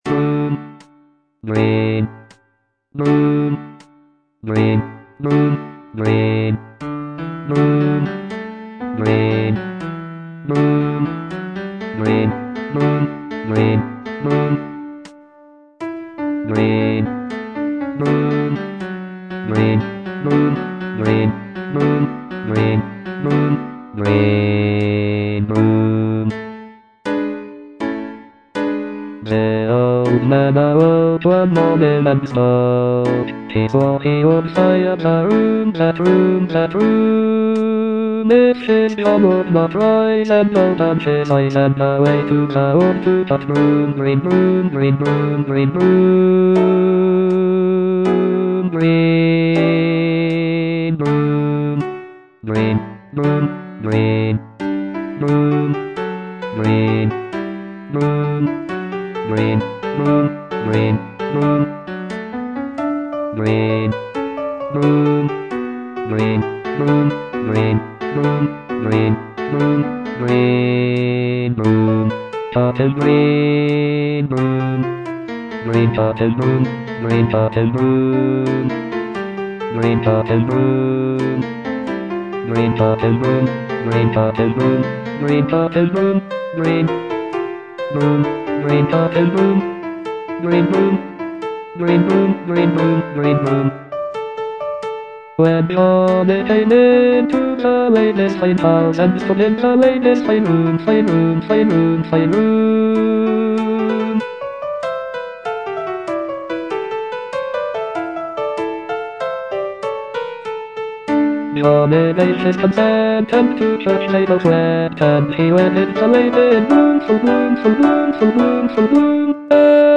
Bass I (Voice with metronome